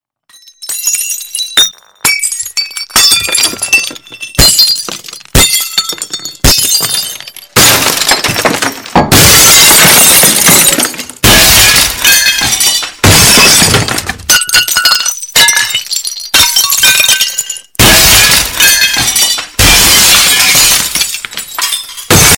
Breaking glass Mp3 Ringtone